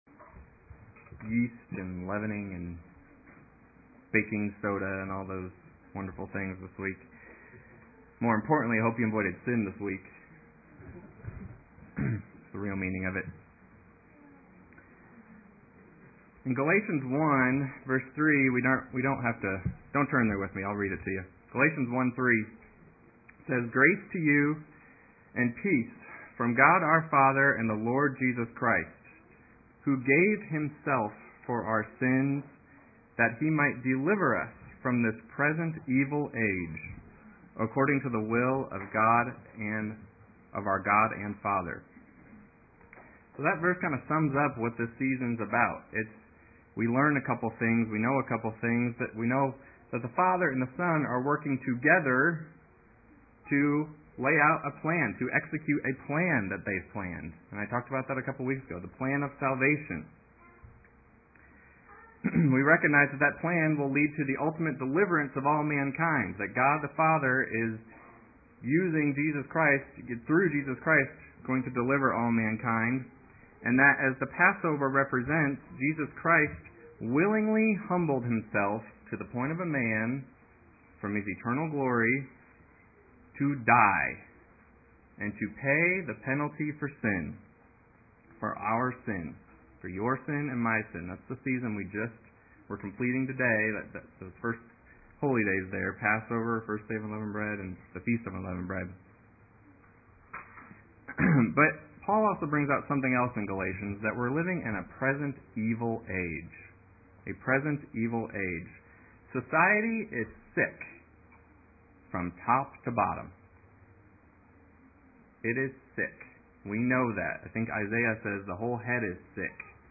Plugging the gap in our spiritual wall, taking a stand against this present evil age. This message was given on the Last Day of Unleavened Bread.
Given in Terre Haute, IN
UCG Sermon Studying the bible?